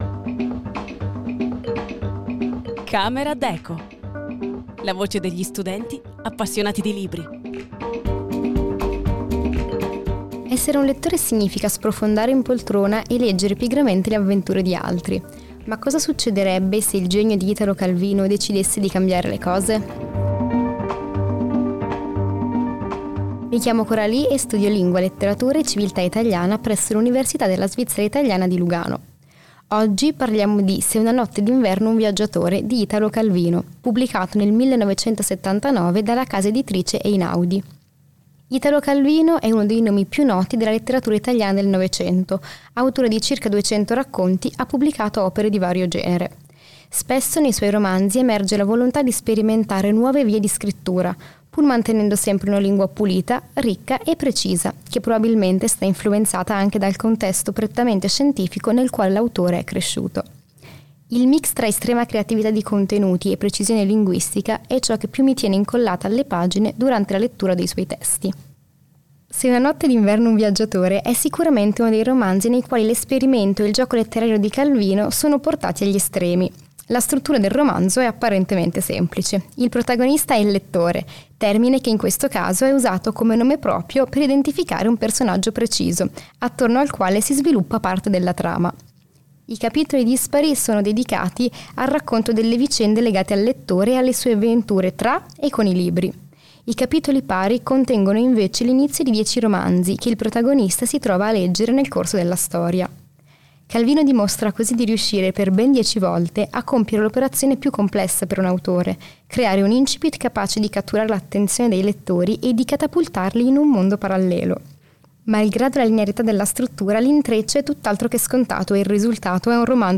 La voce degli studenti appassionati di libri